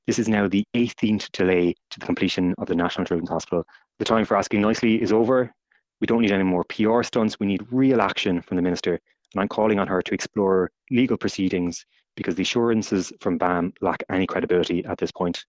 Committee chair Padraig Rice says the Minister needs to go further saying ‘it’s gone beyond a joke at this stage’……………..